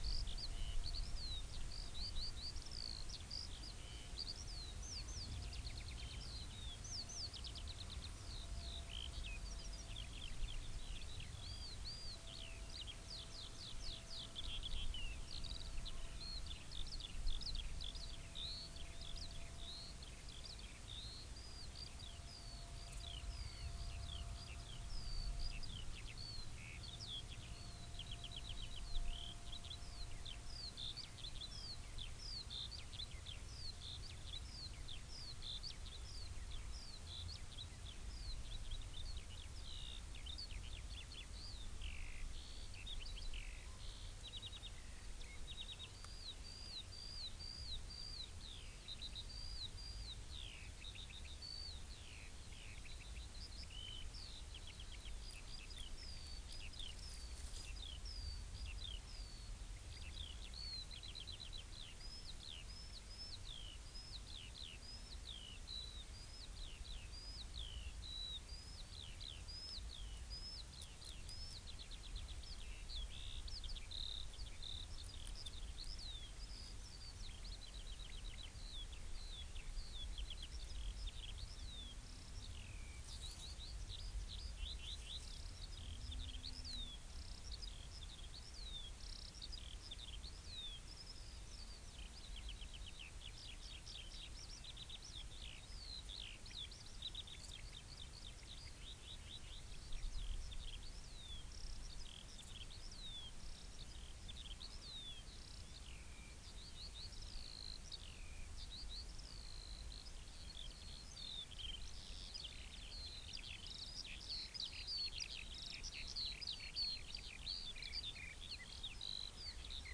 Corvus corone
Coturnix coturnix
Sylvia communis
Emberiza citrinella
Alauda arvensis